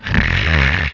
0E_luigi_snoring1.aiff